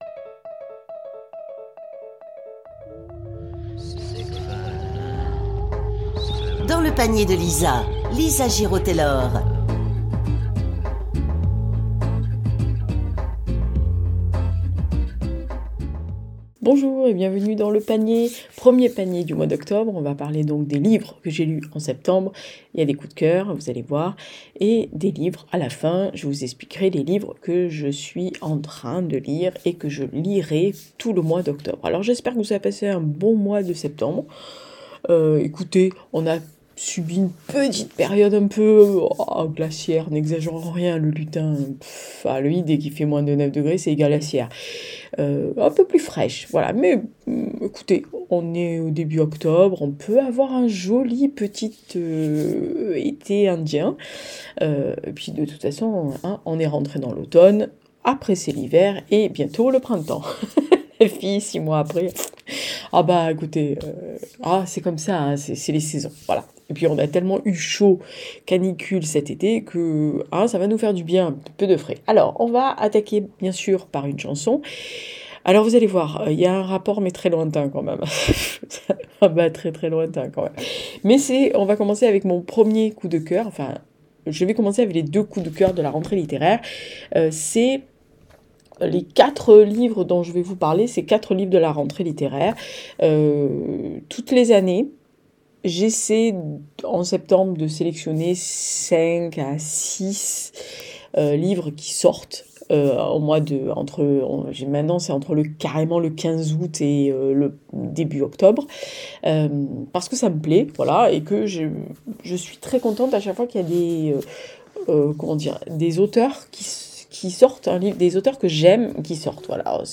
On parle de Jeanne avant Jeanne, Doppelgänger, les promesses orphelines et Adieu Kolyma... 4 romans de la rentrée littéraire... et toujours de la musique pas forcément raccro et le Lutin qui pique !